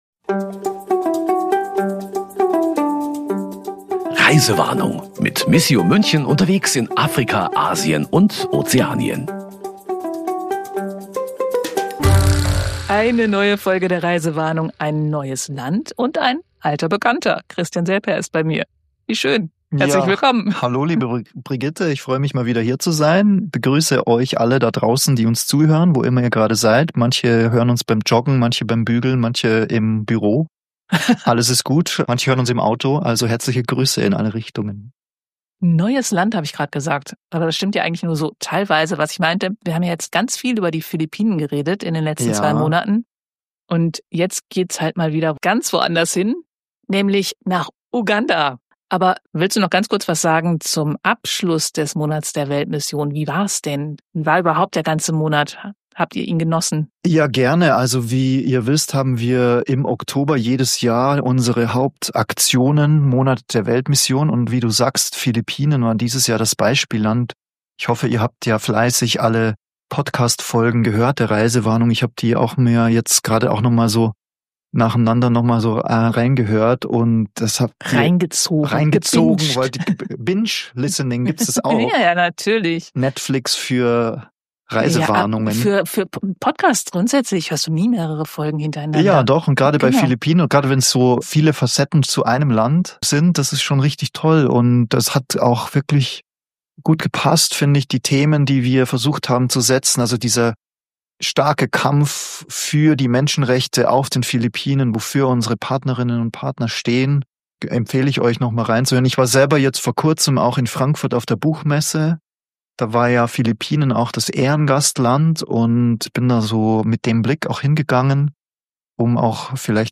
In „Reisewarnung“ erzählen die Reporterinnen und Reporter, was sie auf ihren Reisen erleben. Es geht um Autopannen und verspätete Flugzeuge, um schlaflose Nächte unterm Moskitonetz, und das eine oder andere Experiment im Kochtopf ist auch dabei.